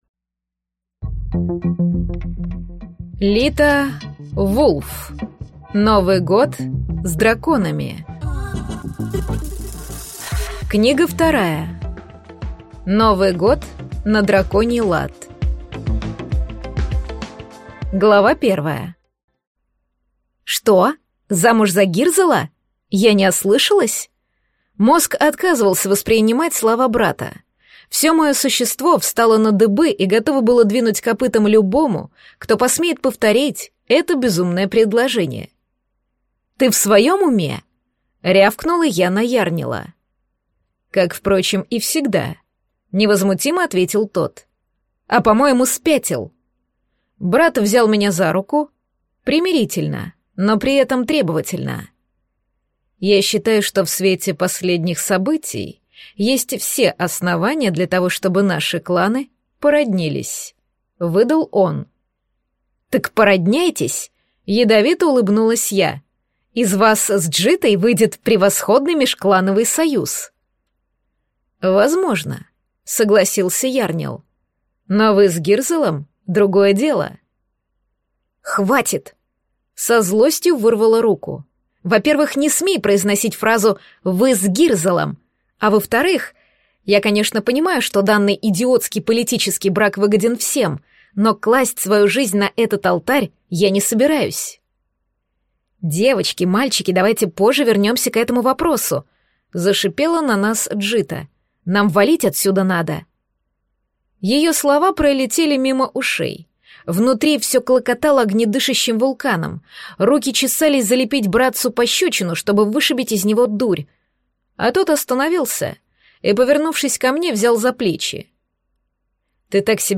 Аудиокнига Новый год на драконий лад | Библиотека аудиокниг